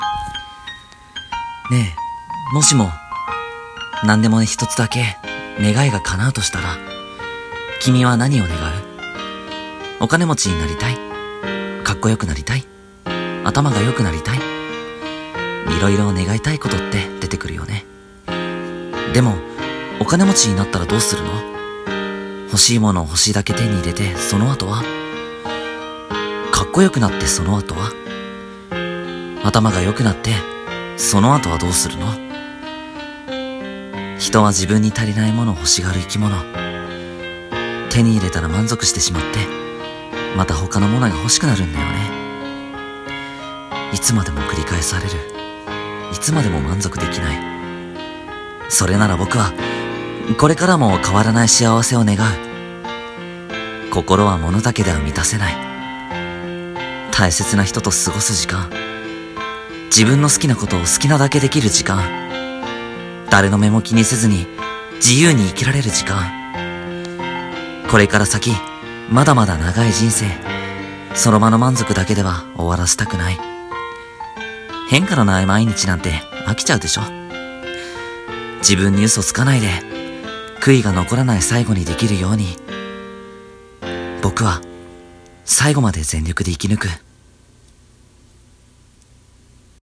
【一人声劇】君の願い事は？